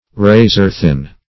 \ra"zor-thin\